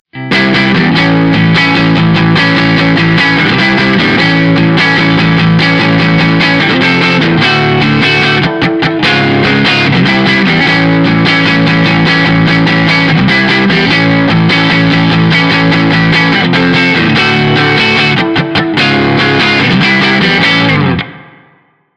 • Low Gain Sensitivity, clean sound
Clean
RAW AUDIO CLIPS ONLY, NO POST-PROCESSING EFFECTS